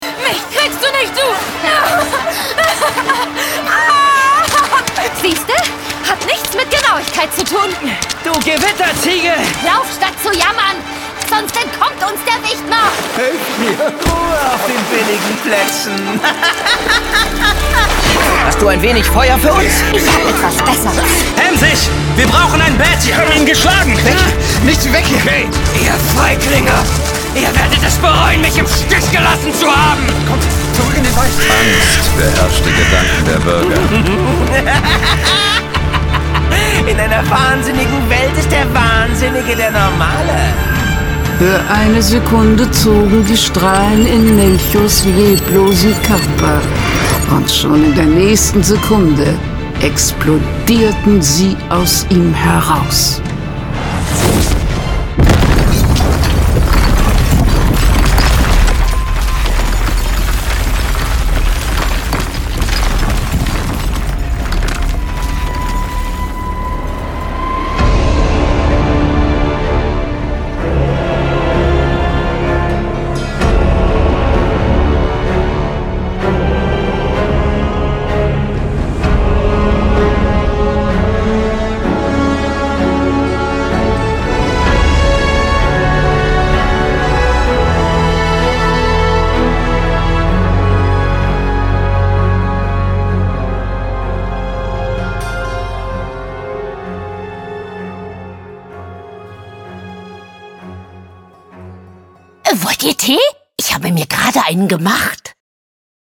Dark Woods · Die Fantasy-Hörspielserie
dw_teaser.mp3